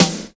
74 SNARE.wav